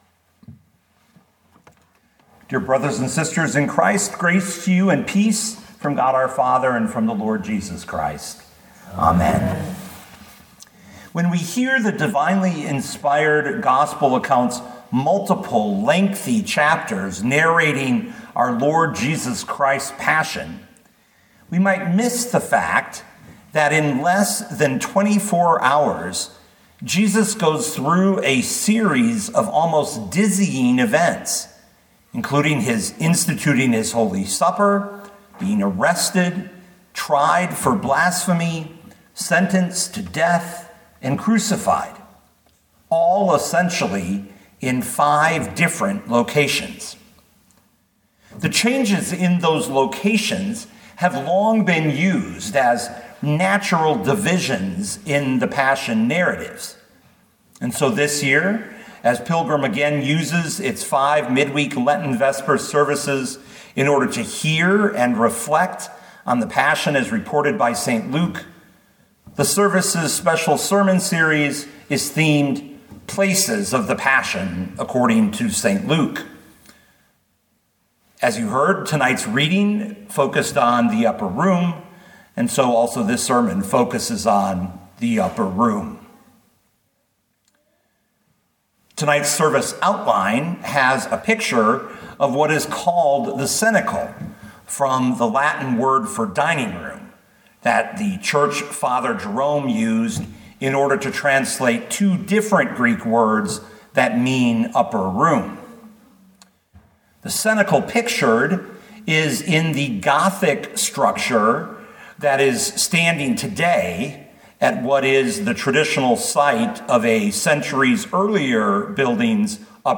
2025 Luke 22:1-38 Listen to the sermon with the player below, or, download the audio.